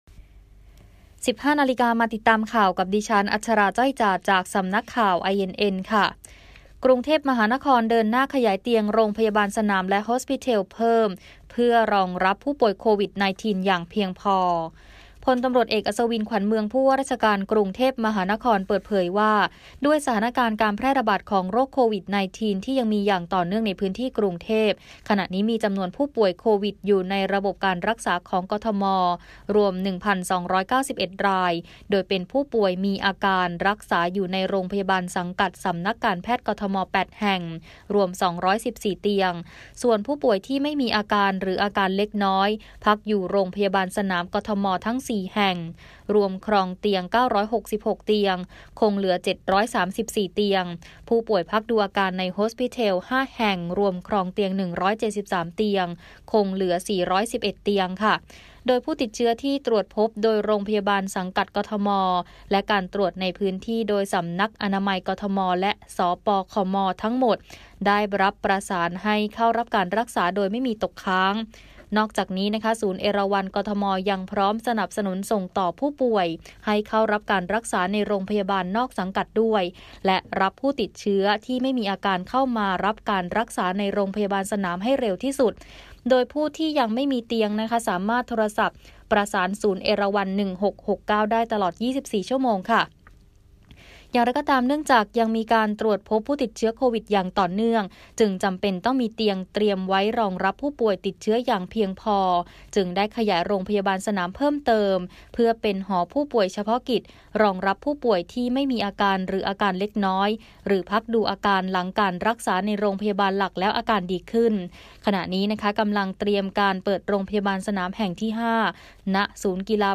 คลิปข่าวต้นชั่วโมง
ข่าวต้นชั่วโมง 15.00 น.